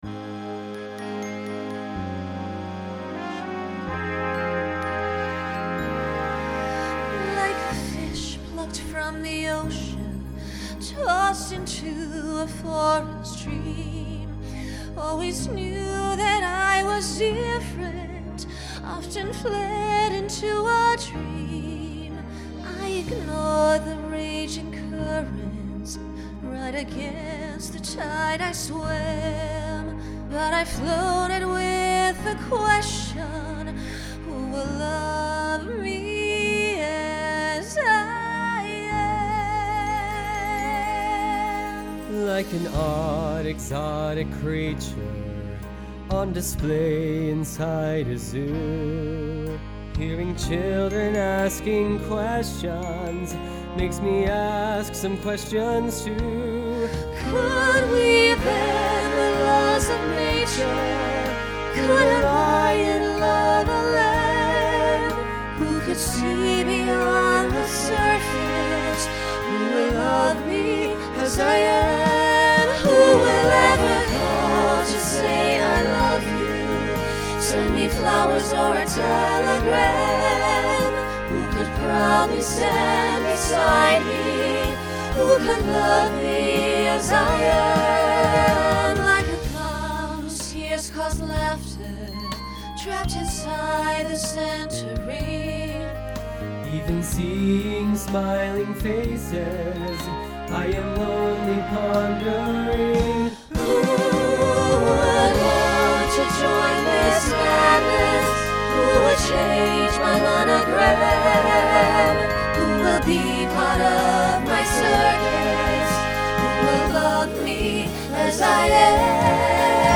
Voicing SATB Instrumental combo Genre Broadway/Film
Function Ballad